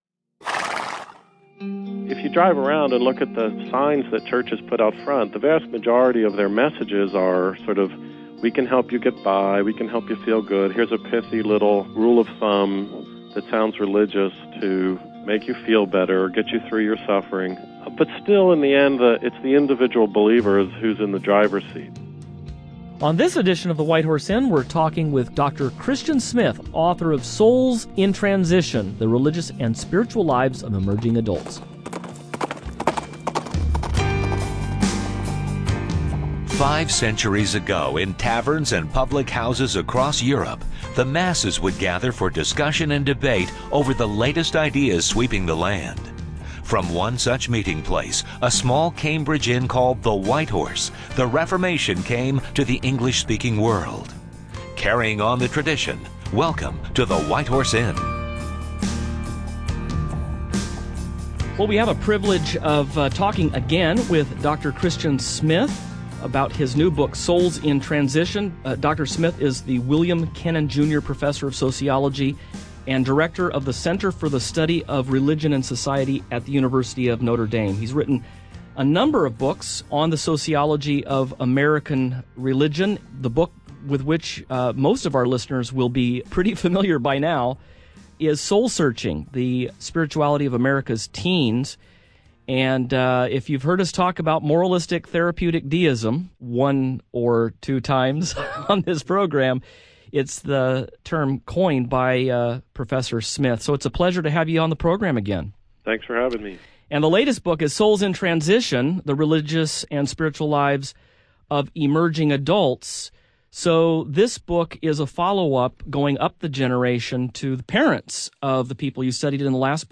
What is an emerging adult, and how have the beliefs and religious practices of this group changed in recent decades? What effect does our contemporary culture have on the religious lives of young people in our day? Joining the panel to discuss this topic is University of Notre Dame professor…